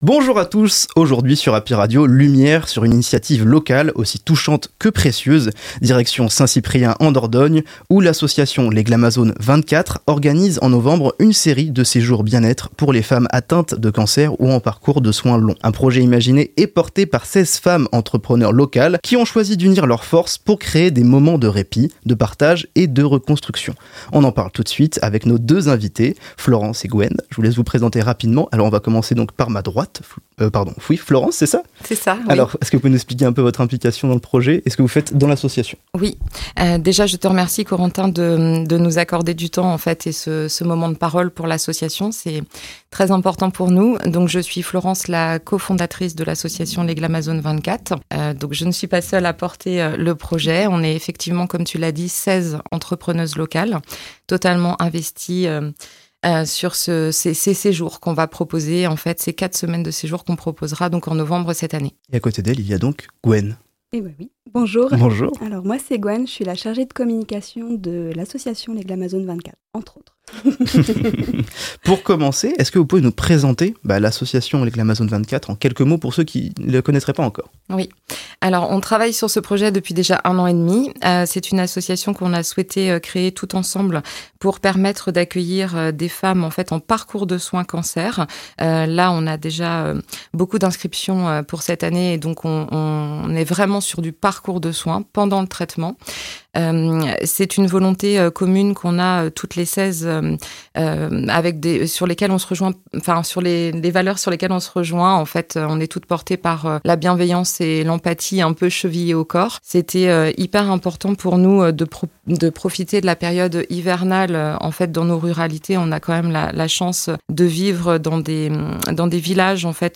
Les interviews Happy Radio – Les Glamazones 24